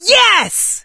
leon_kill_vo_06.ogg